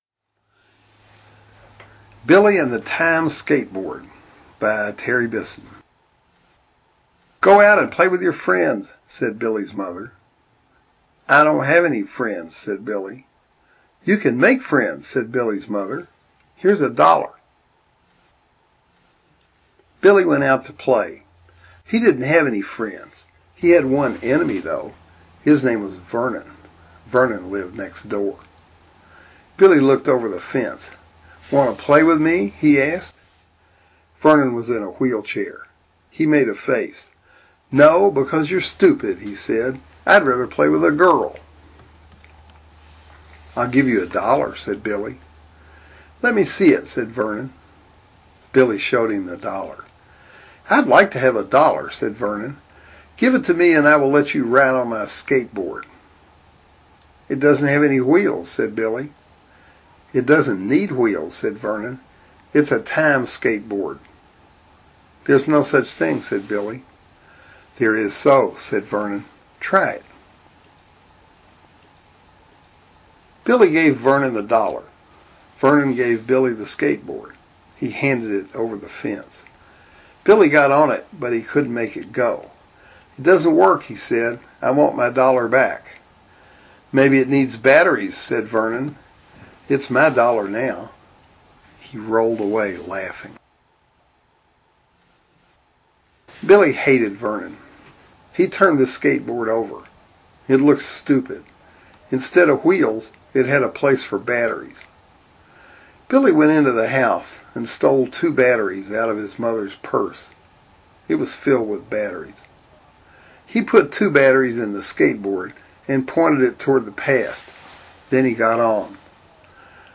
Terry Bisson Reads Billy’s Book Stories